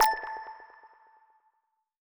button-hover.wav